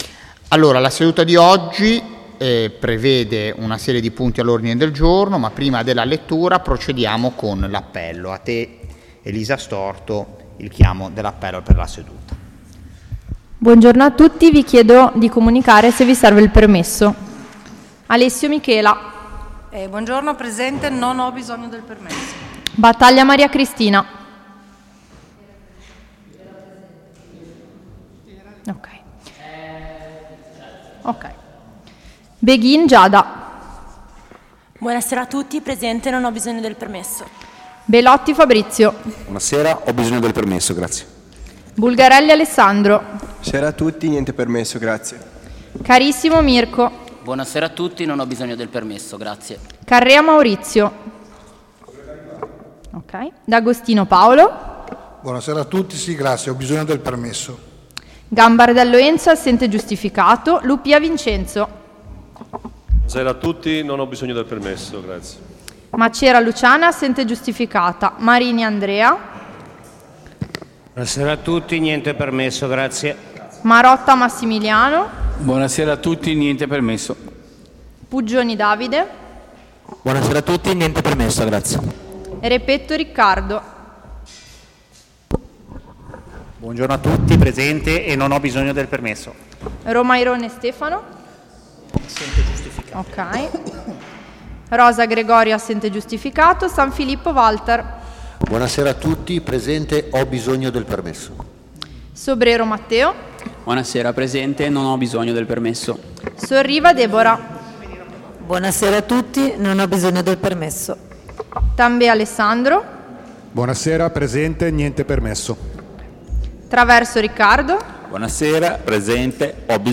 Luogo: Sala Consiliare in Via Guido Poli 12
Convocazioni: convocazione_consiglio_14_novembre_2024_pubblicazione.pdf Audio seduta: seduta_del_14_novembre_2024_online-audio-converter.com_.mp3